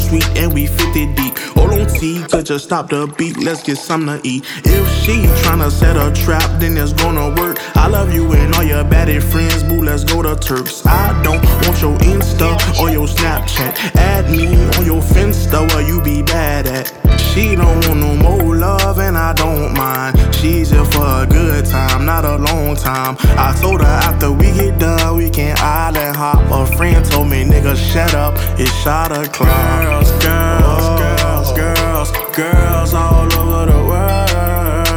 Жанр: Рэп и хип-хоп / Украинские